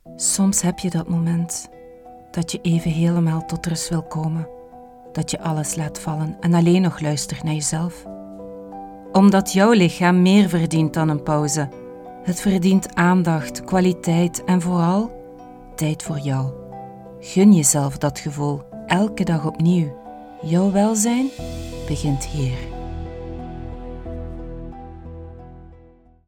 Vous pourrez compter sur un enregistrement impeccable de la voix néerlandaise flamande (belge), la voix française, la voix anglaise ou la voix espagnole grâce à l’équipement professionnel dont je dispose.
Démo Pub – Exemple voix off en Néerlandais (Flamand )